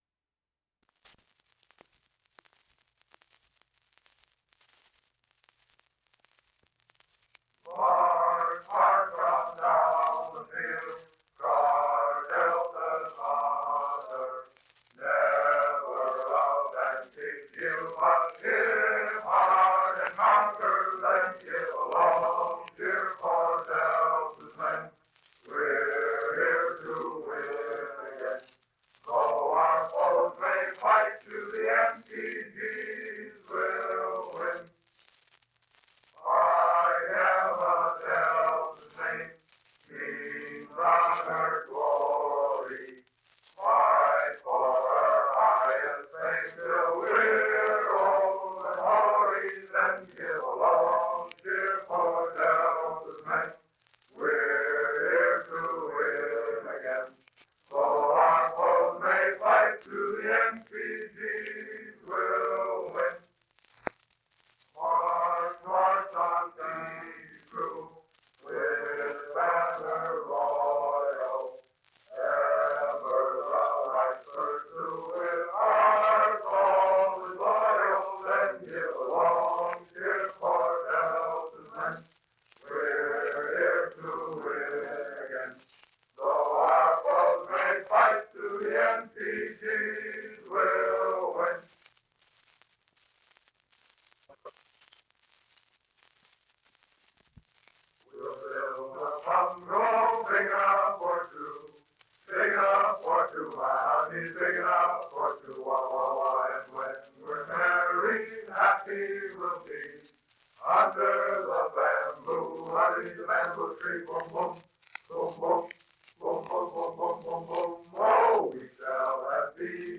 Old Recordings of Chi Upsilon Fijis Singing
Fiji Songs And here is a faint recording from 1941:
1941 MARCH BUNGALOW and THISTLE 2017 improved transfer from 78 RPM disc